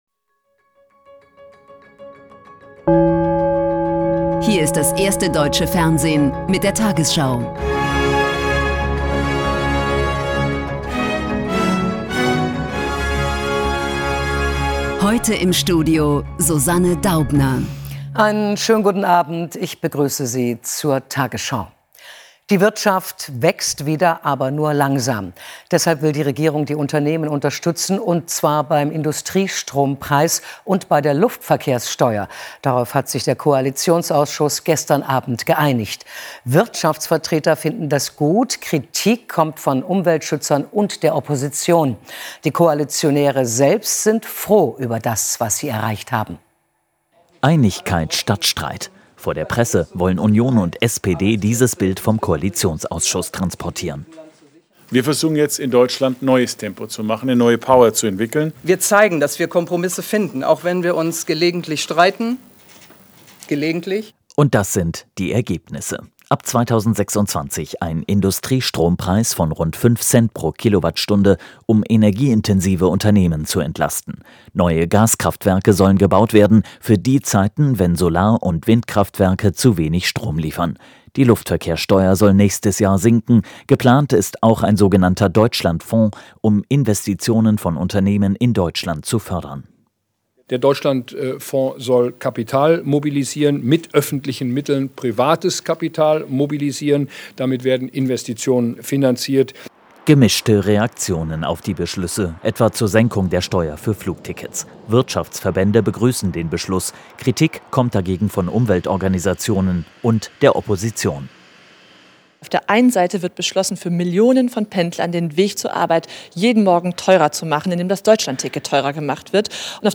tagesschau 20:00 Uhr, 14.11.2025 ~ tagesschau: Die 20 Uhr Nachrichten (Audio) Podcast